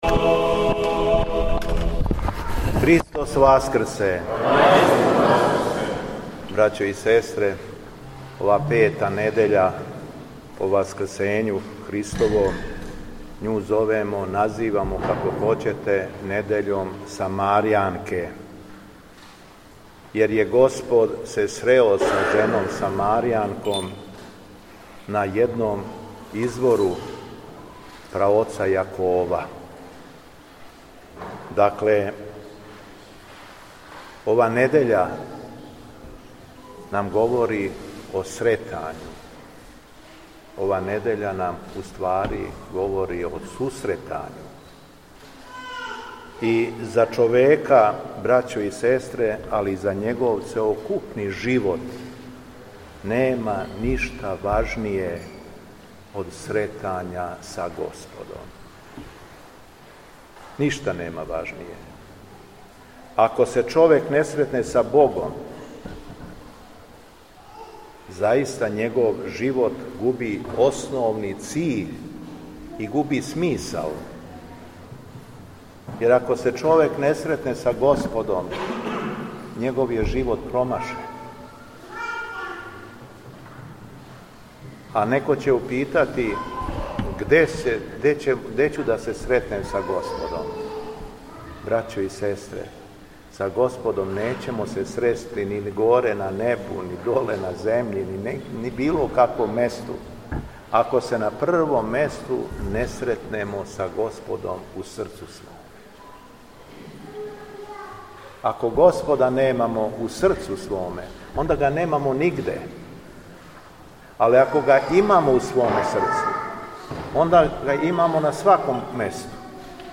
Беседа Његовог Високопреосвештенства Митрополита шумадијског г. Јована
У недељу, пету по Васкрсу – Самарјанке, 18. маја 2025. године, Његово Високопреосвештенство Митрополит шумадијски господин Јован служио је архијерејску Литургију у храму Светог апостола и јеванђелисте Марка у Великим Црљенима, надомак Лазаревца.